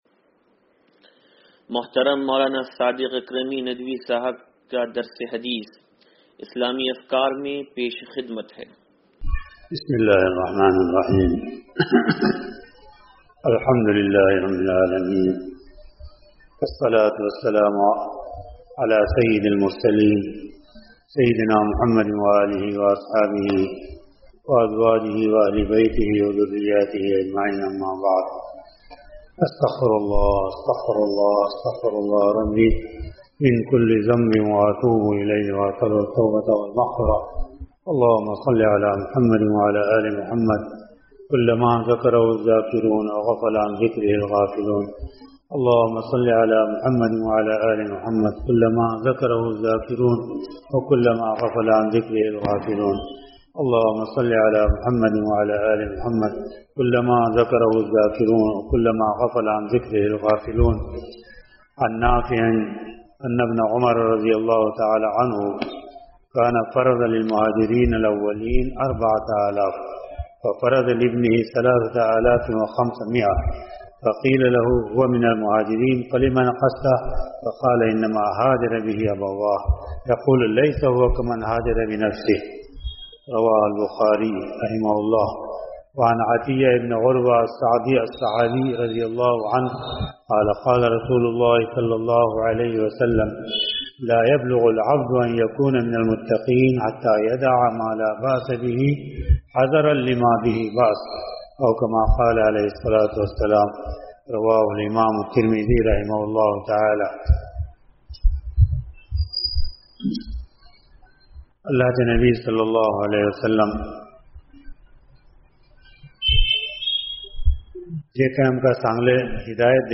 درس حدیث نمبر 0590